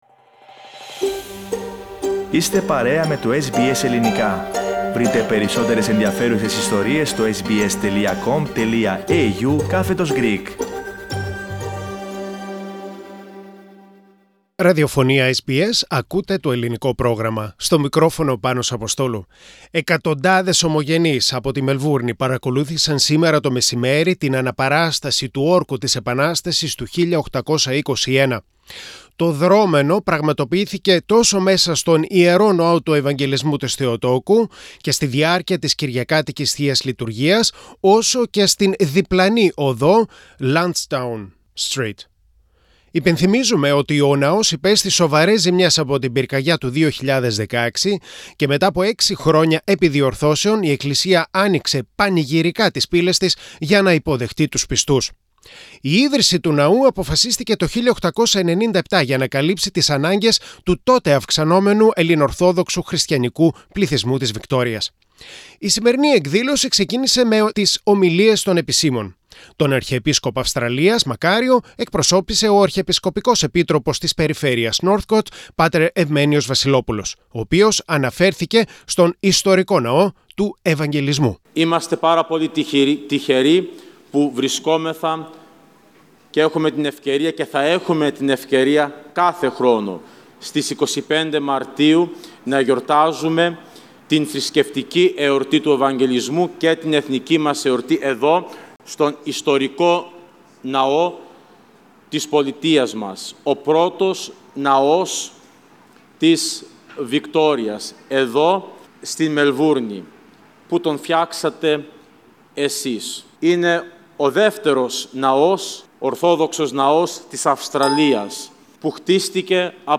On Sunday 28 March, Lansdowne Street in East Melbourne was transformed into a place bursting with colour, dancing, and music in an event that commemorates the 200th Anniversary of the Greek War of Independence.